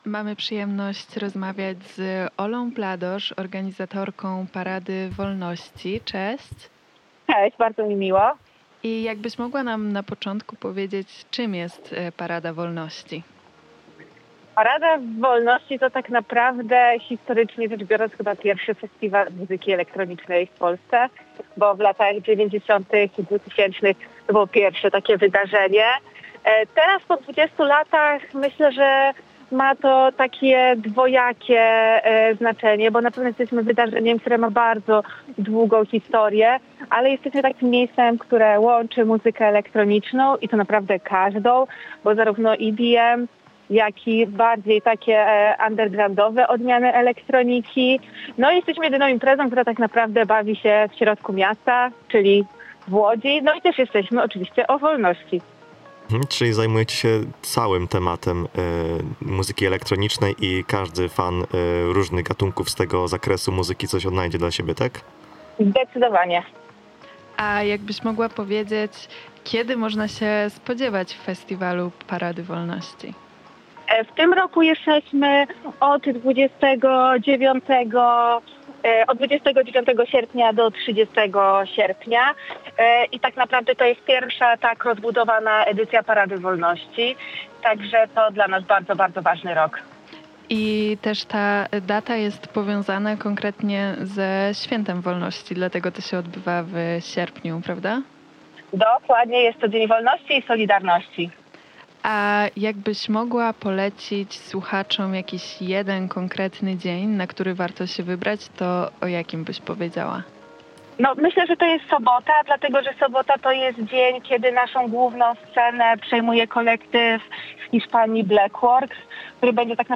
Na naszej antenie